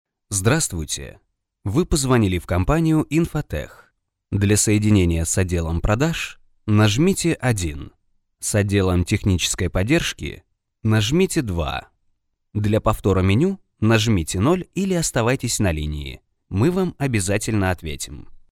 Автоответчик